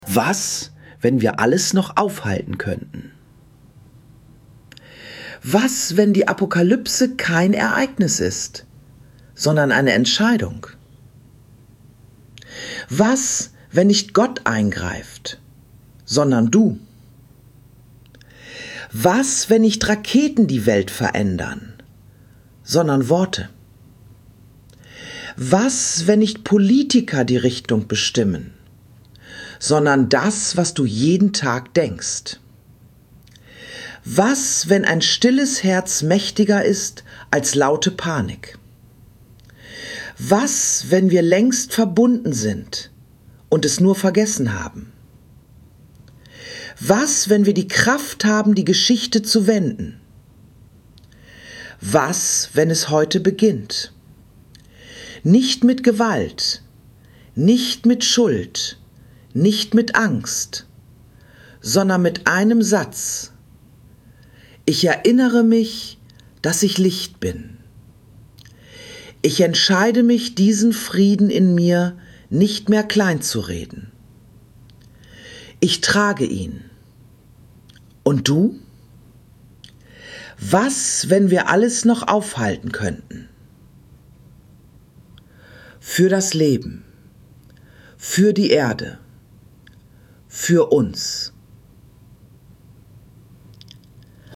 Für blinde oder sehbehinderte Menschen bieten wir hier eine gesprochene Version an.
Gesprochene Version (MP3)